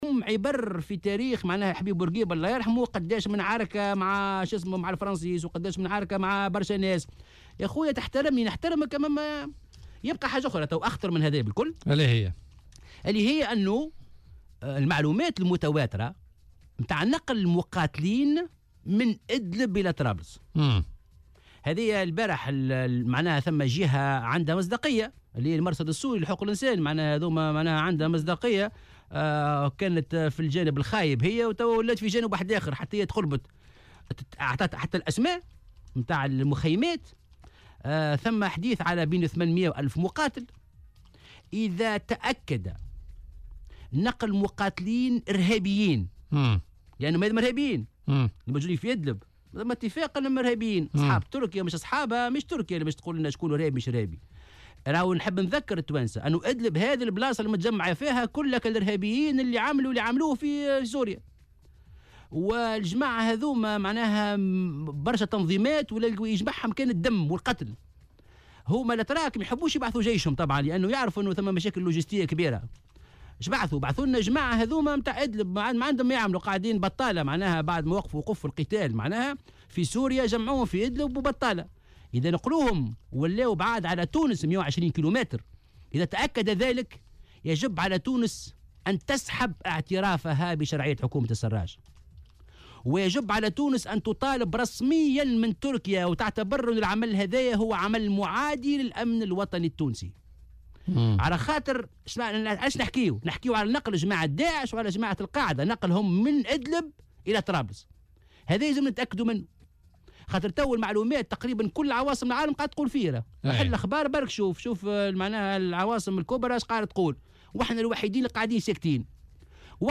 وتابع ضيف"بوليتيكا" على "الجوهرة اف ام" أنه يتم الحديث حاليا عن نحو ألف مقاتل ارهابي كانوا مجمعين في ادلب معقل الارهابيين في سوريا، وستستخدمهم تركيا في تحركها العسكري الميداني المزمع في طرابلس، مشدّدا بالقول إنهم من أخطر المقاتلين الدمويين في سوريا الذي عملوا على تخريبها، داعيا الرئيس التونسي قيس سعيّد للتحرّك فورا.